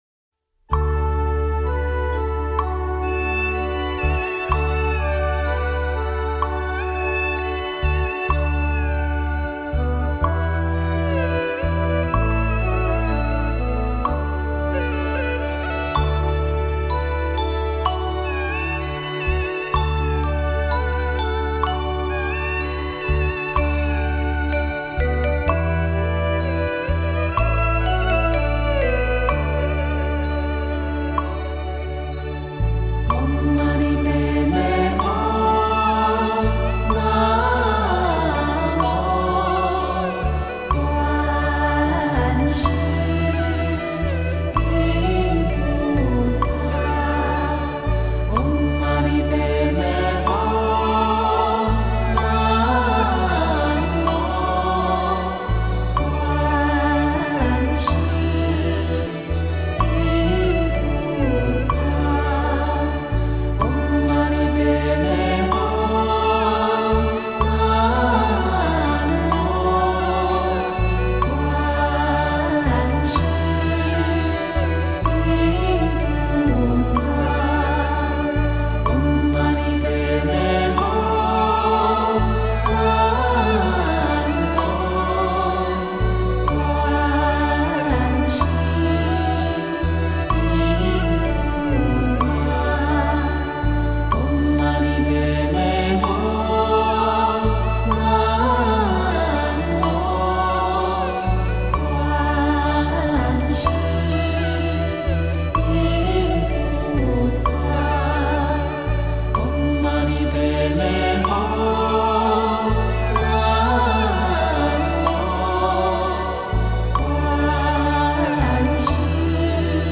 LZDMZ21.mp3 檔案下載 - 佛學多媒體資料庫 佛學多媒體資料庫 > 佛曲音樂 > 真言咒語 > 六字大明咒(觀音菩薩心咒) > 六字大明咒-一音 > LZDMZ21.mp3 > 檔案下載 Download 下載: LZDMZ21.mp3 ※MD5 檢查碼: 621A080F858B10E02BB38086ACF6F0B2 (可用 WinMD5_v2.exe 檢查下載後檔案是否與原檔案相同)